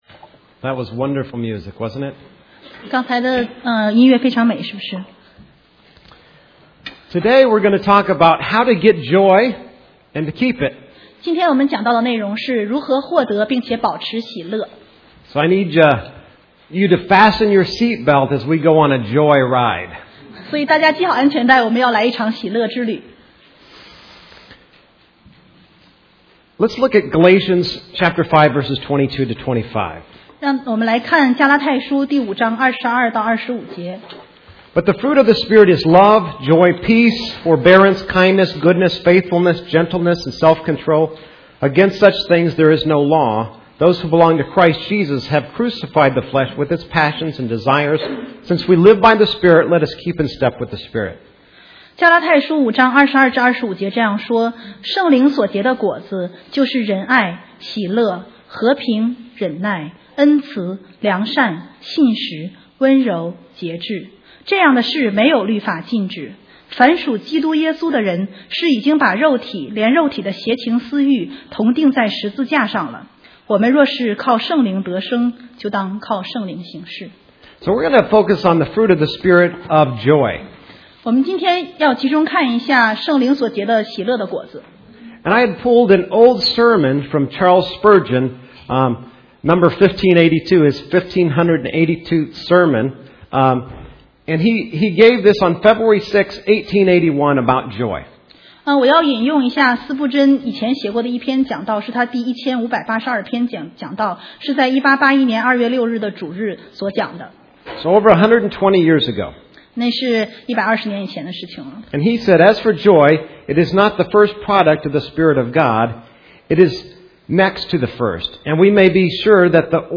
中文讲道